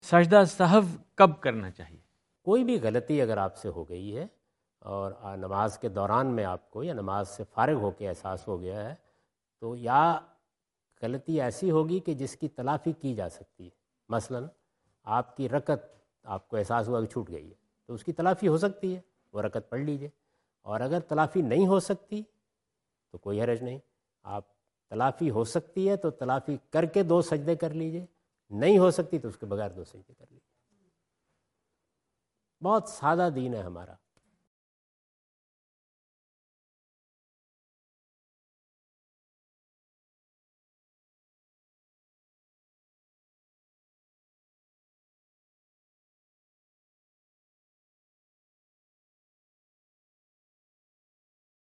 جاوید احمد غامدی اپنے دورہ امریکہ2017 کے دوران فلیڈیلفیا میں "سجدہ سہو" سے متعلق ایک سوال کا جواب دے رہے ہیں۔